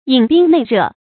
饮冰内热 yǐn bīng nèi rè 成语解释 形容十分惶恐焦灼。